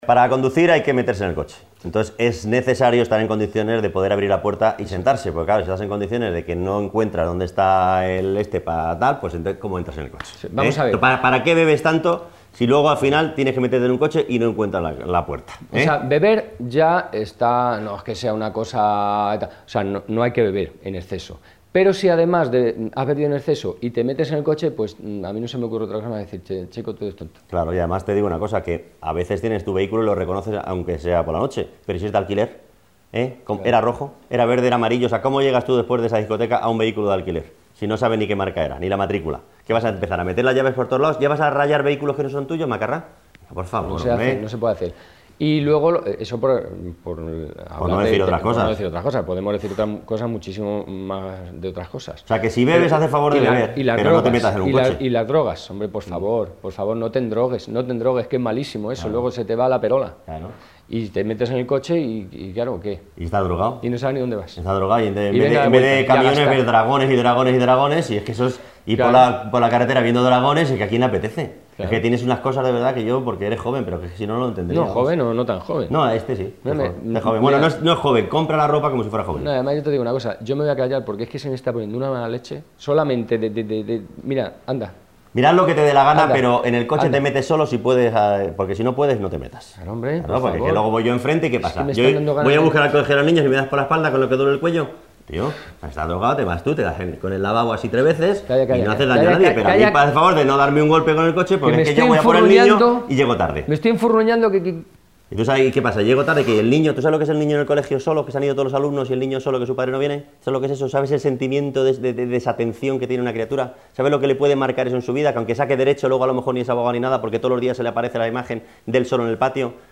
El dúo humorístico, formado por Juan Luis Cano y Guillermo Fesser presentan con su característico humor una serie de consejos para una conducción segura. haciendo especial hincapié en evitar el consumo de alcohol y drogas en la conducción.